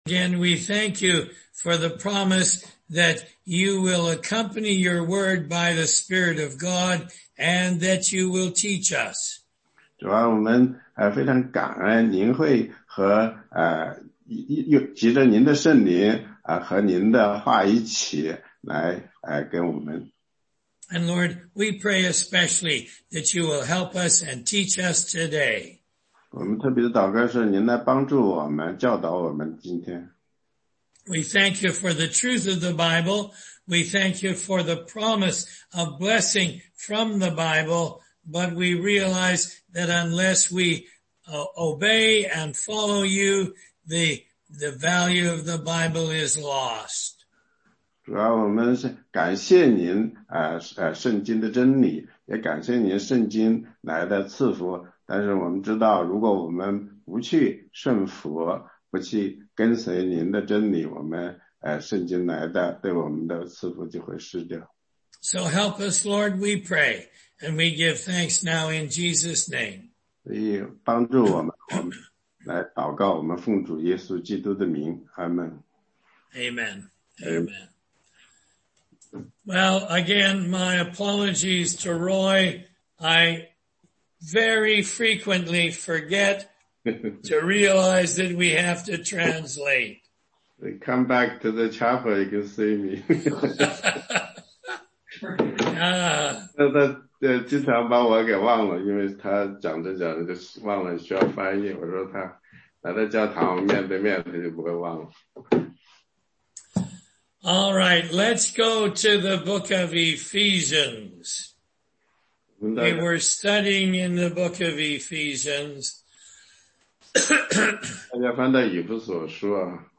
答疑课程